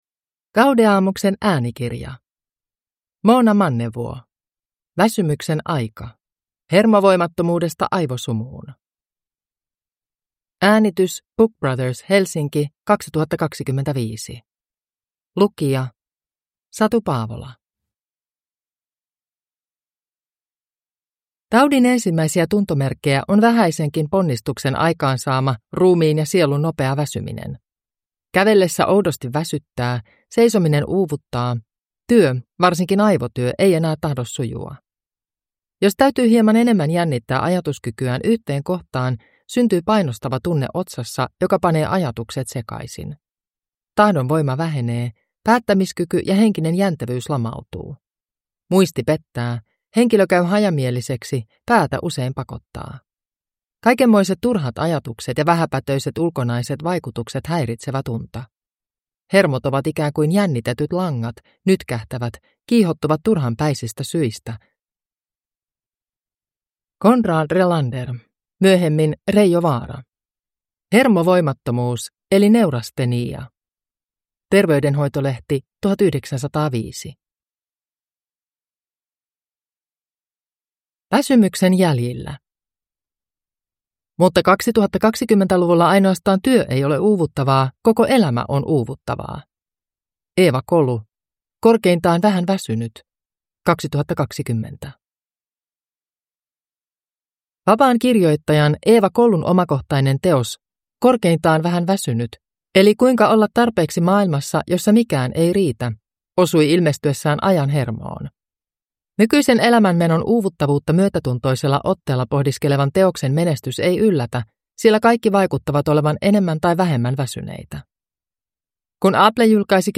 Väsymyksen aika – Ljudbok